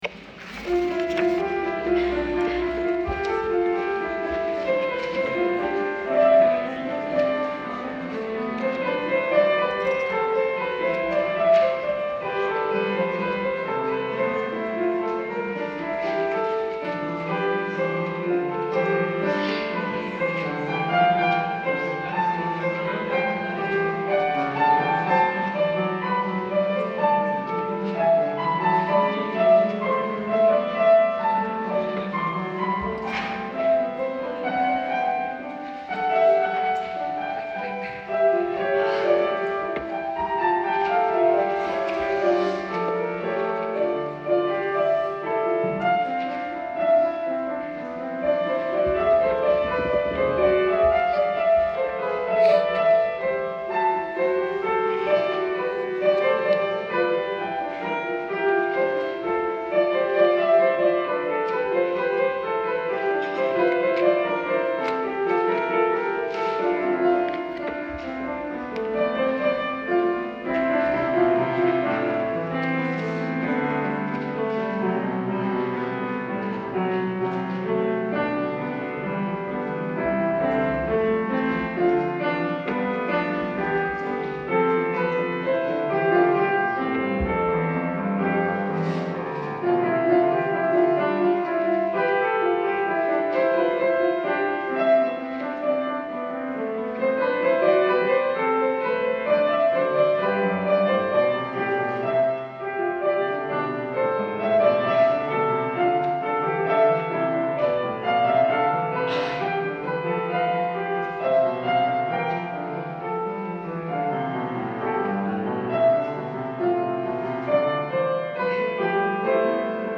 Extraits audio du culte du 15 décembre 2024.mp3 (77.06 Mo)